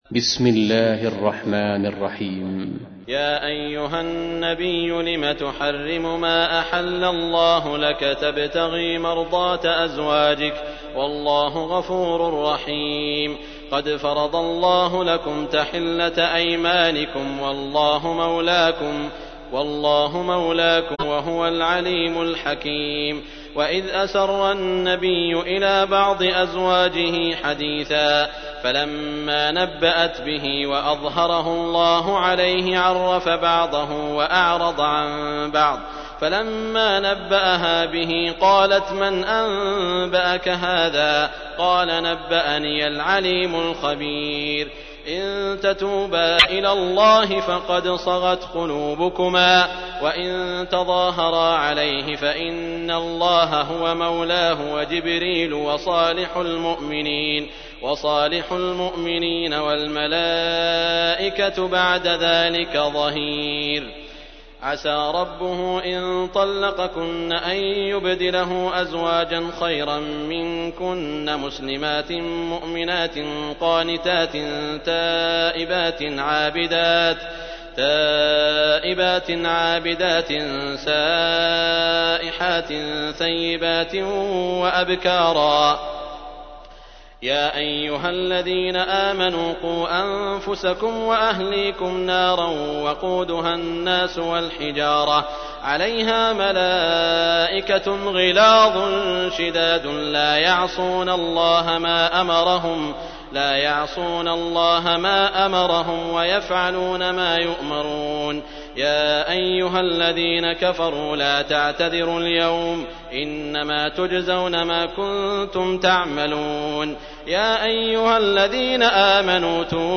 تحميل : 66. سورة التحريم / القارئ سعود الشريم / القرآن الكريم / موقع يا حسين